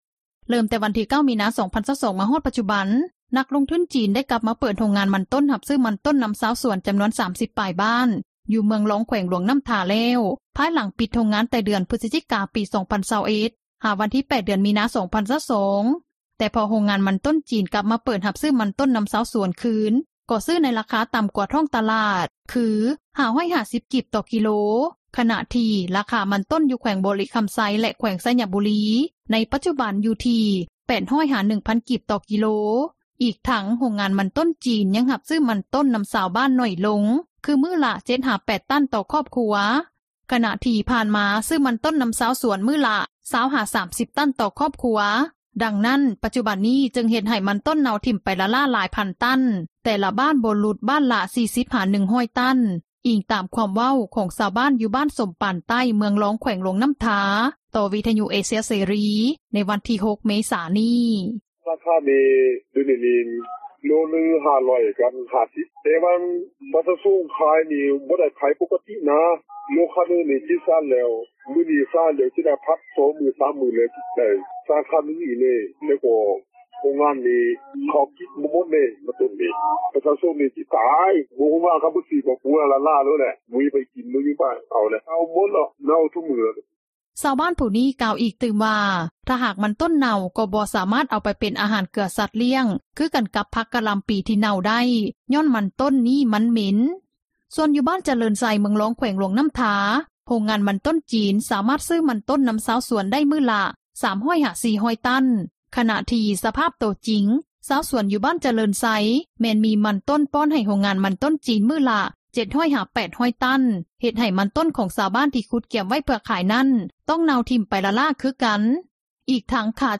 ດັ່ງຊາວບ້ານ ຢູ່ບ້ານຈະເຣີນໄຊ ເມືອງລອງ ແຂວງຫຼວງນໍ້າທາ ກ່າວໃນມື້ດຽວກັນນີ້ວ່າ:
ດັ່ງຊາວສວນມັນຕົ້ນ ຢູ່ບ້ານເຕົ້າໂຮມ ເມືອງລອງ ແຂວງຫຼວງນໍ້າທາ ກ່າວໃນມື້ດຽວກັນນີ້ວ່າ: